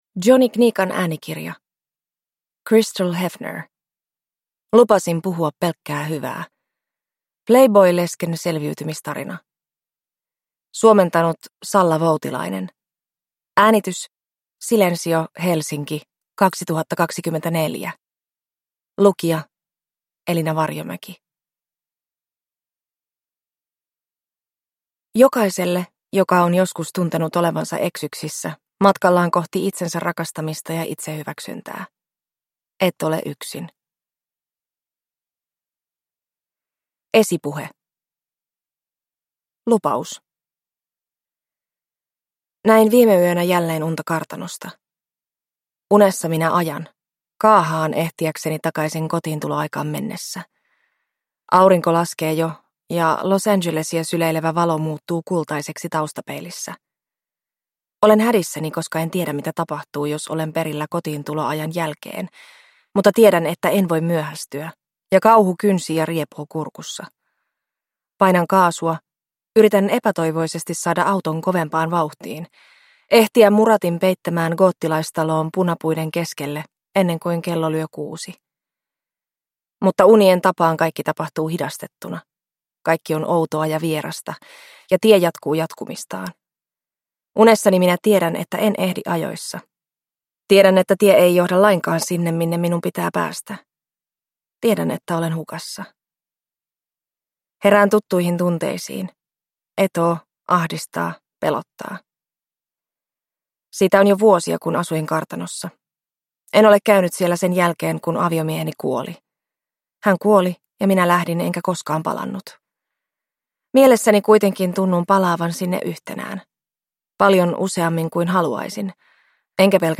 Lupasin puhua pelkkää hyvää – Ljudbok